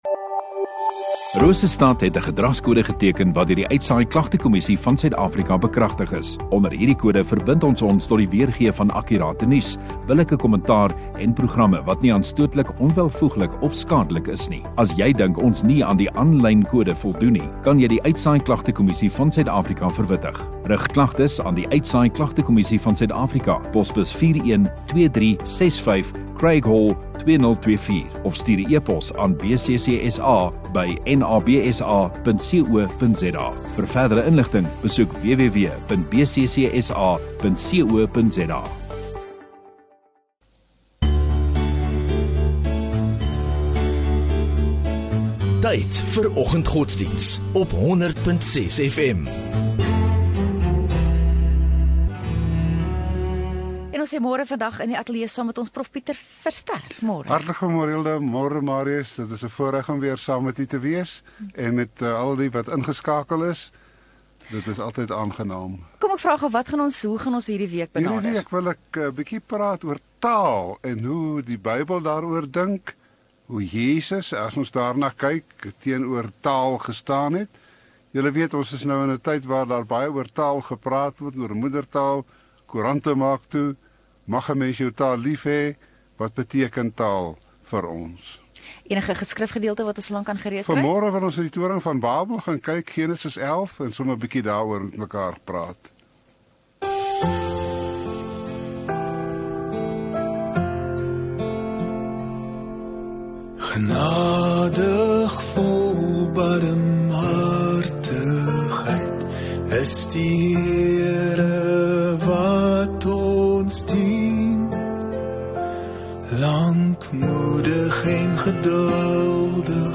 5 Aug Maandag Oggenddiens